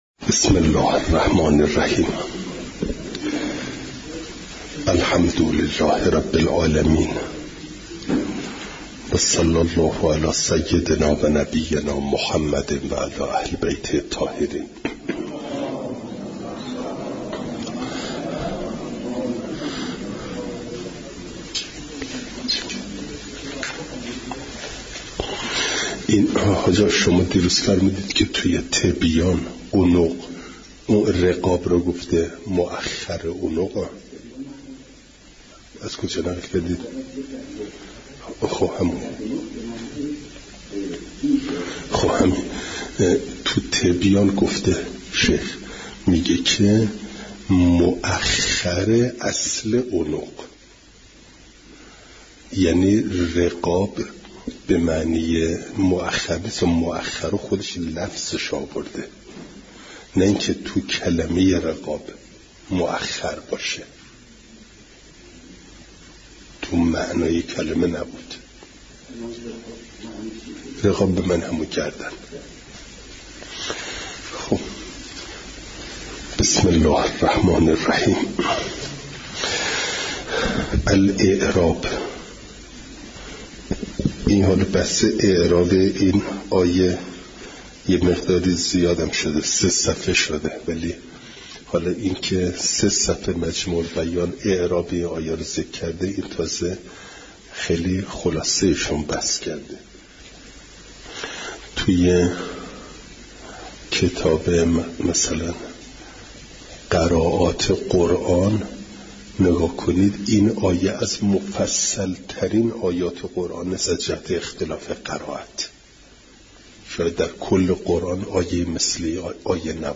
فایل صوتی جلسه صد و هفتاد و دوم درس تفسیر مجمع البیان